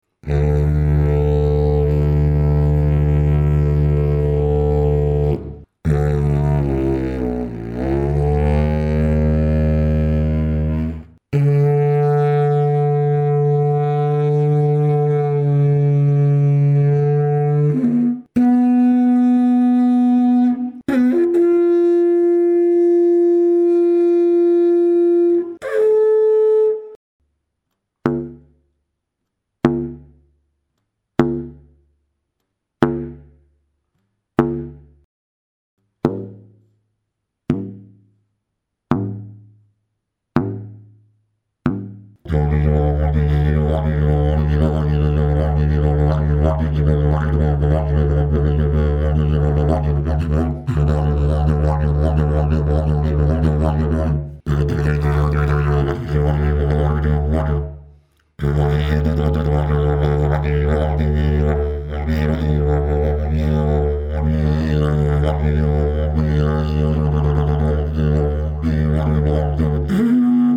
Grundton, Ziehbereiche und Overblows bei 24grd C: D2+5 (Bn1 bis D#2+20) // D3 +-0 (-40, +10) / Bn3+-0 / G4-10 / A4-30 This Dg518 is a didgeridoo of my model 049, tuned to D2, with the overblow on the octave D3. The very stable and easily playable fundamental tone has very effective resonance resistance. Despite the narrow cone shape, the first overblow is located on the octave. This shape produces a calmer, less boomy fundamental tone that can be easily modulated with vocal effects and therefore does not easily distort microphones.
Fundamental note, pull ranges and overblows at 24� C: D2+5 (Bn1 to D#2+20) // D3 �0 (-40, +10) / Bn3 �0 / G4-10 / A4-30 Dg518 Technical sound sample 01